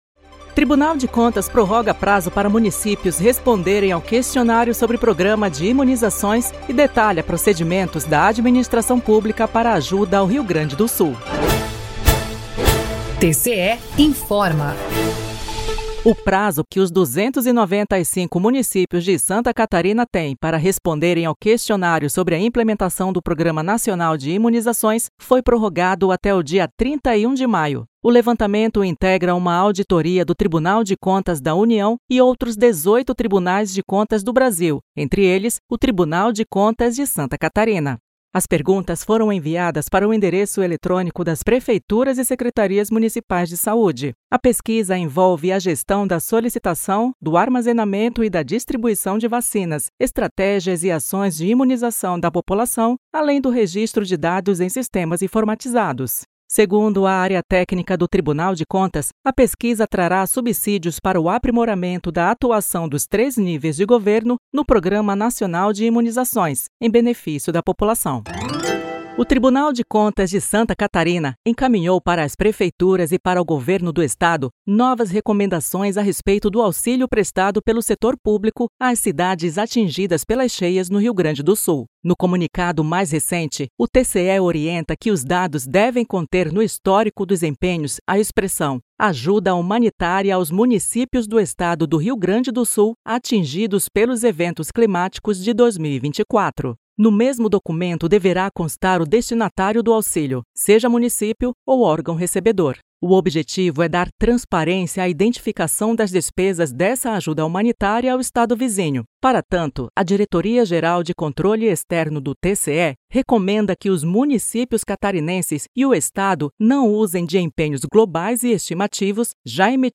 VINHETA TCE INFORMA
SINAL SONORO
VINHETA TCE INFORMOU